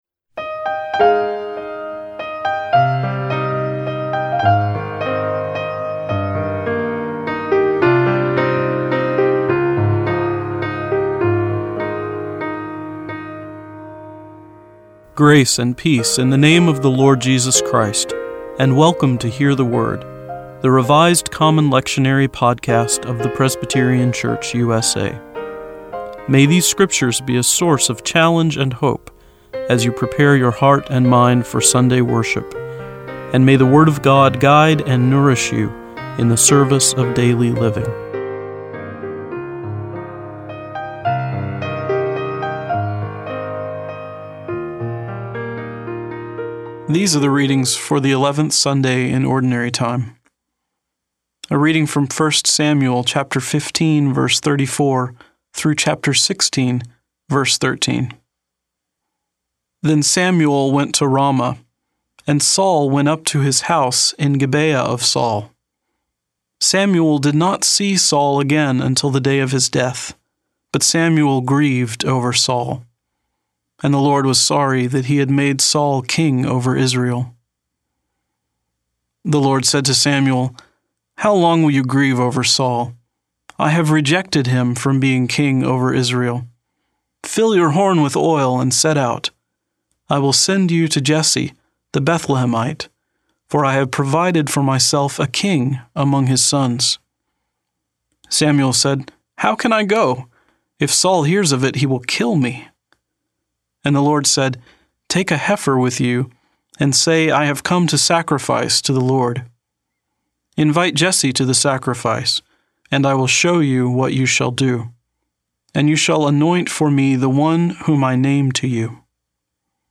Each podcast (MP3 file) includes four lectionary readings for one of the Sundays or festivals of the church year: an Old Testament reading, a Psalm, an Epistle and a Gospel reading. Following each set of readings is a prayer for the day from the Book of Common Worship.